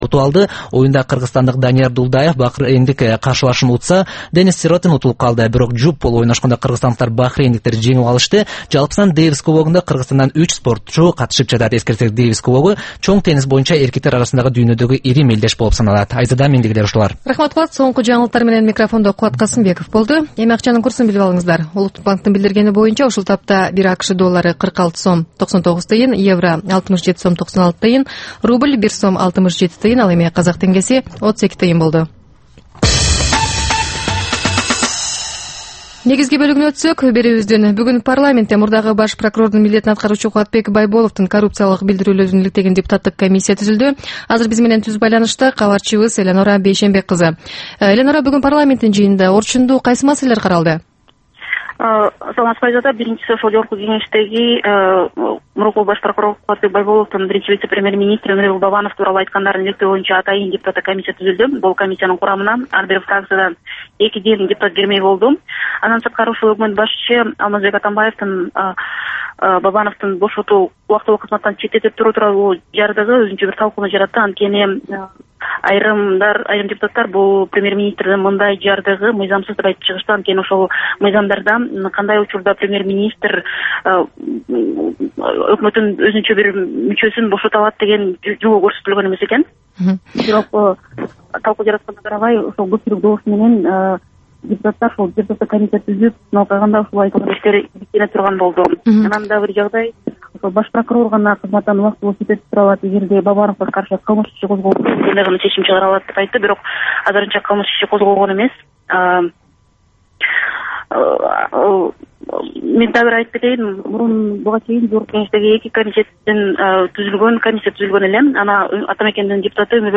Бул түшкү үналгы берүү жергиликтүү жана эл аралык кабарлар, ар кыл орчун окуялар тууралуу репортаж, маек, талкуу, кыска баян жана башка оперативдүү берүүлөрдөн турат. "Азаттык үналгысынын" бул түш жаңы оогон учурдагы берүүсү Бишкек убакыты боюнча саат 13:00төн 13:30га чейин обого чыгарылат.